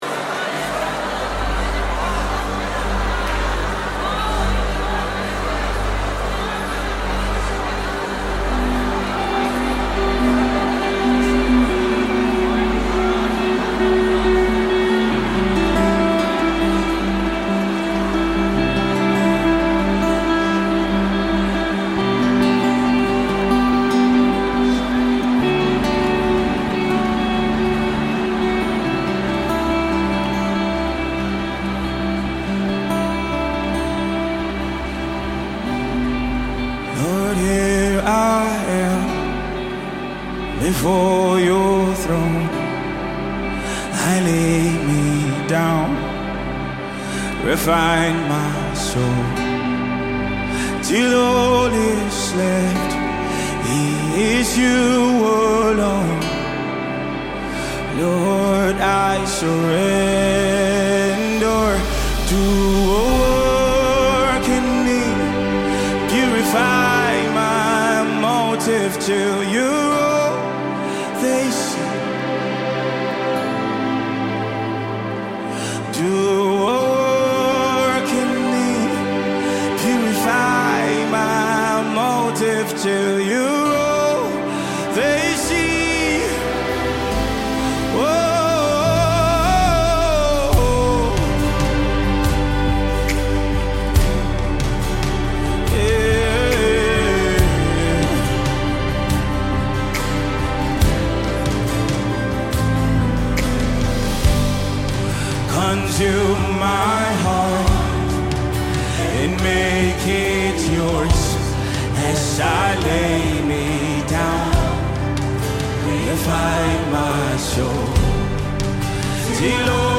acoustic warmth with a mood of deep spiritual reflection